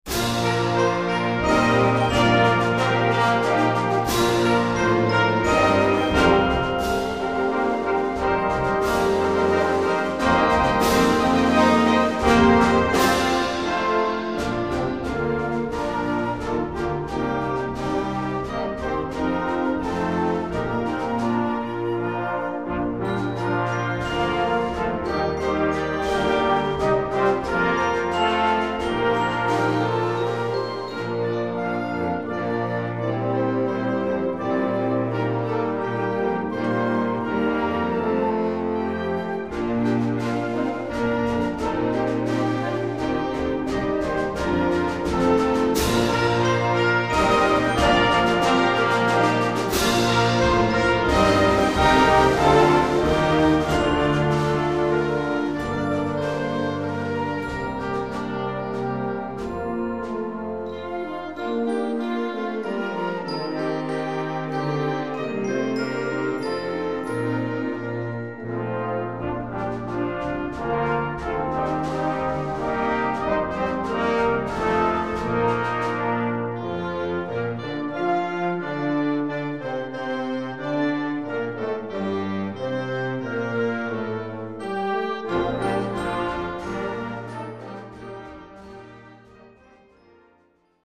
Répertoire pour Harmonie/fanfare - Concert Band